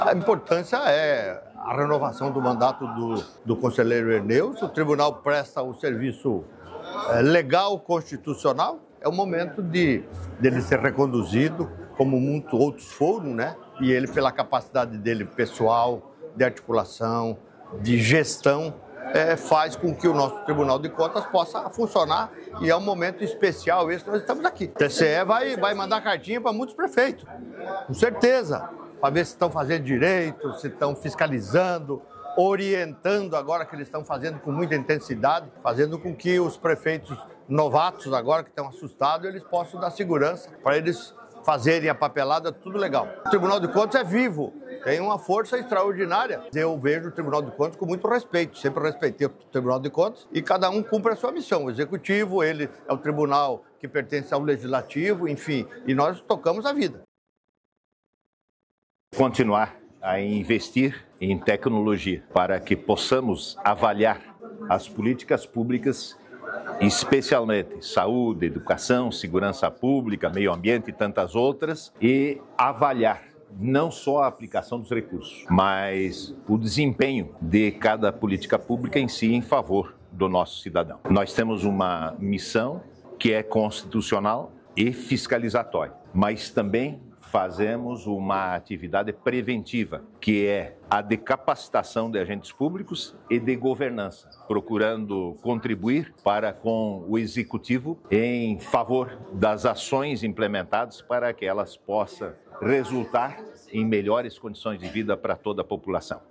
Governador participa da posse da diretoria do Tribunal de Contas de Santa Catarina
O governador Jorginho Mello valorizou o trabalho do Tribunal de Contas:
O presidente do TCE/SC, Herneus de Nadal, salientou que o tribunal vai continuar a investir em tecnologia para avaliar as políticas públicas:
SECOM-Sonoras-Gov-e-presidente-TCE-SC-Posse-da-Diretoria.mp3